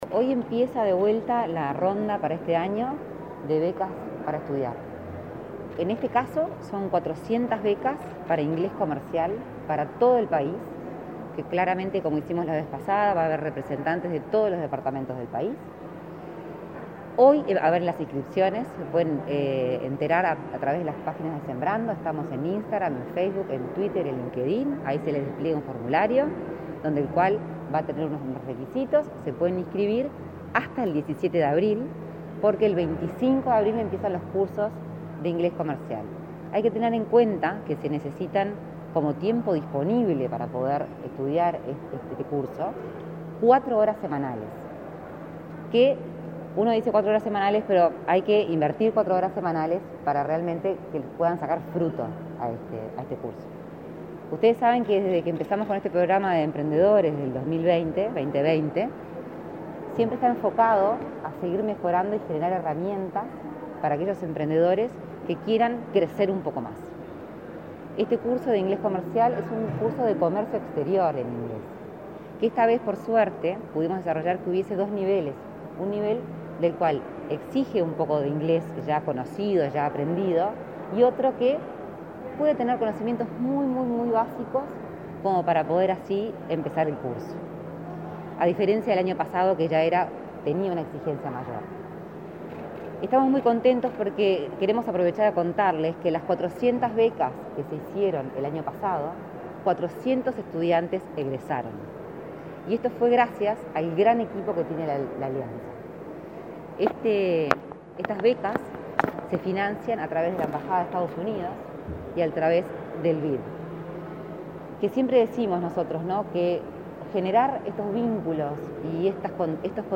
Declaraciones a la prensa de Lorena Ponce de León